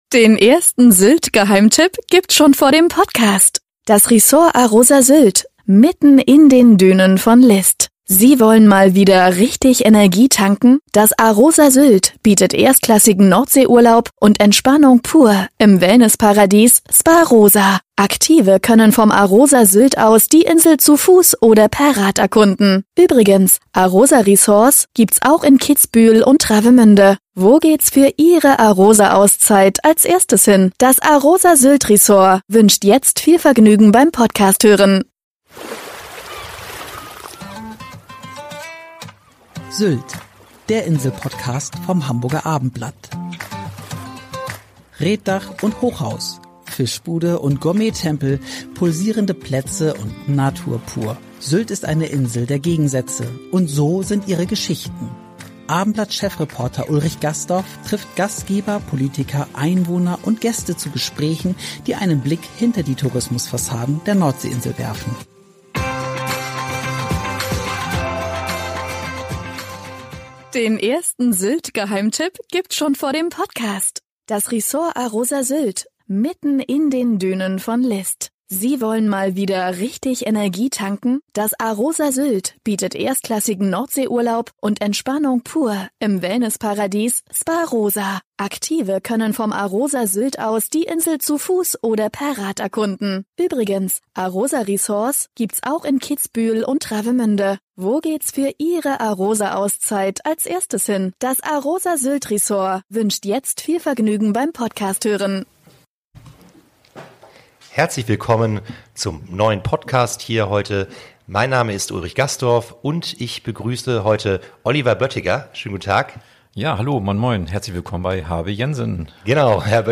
Gespräche, die einen Blick hinter die Tourismus-Fassaden der Nordsee-Insel werfen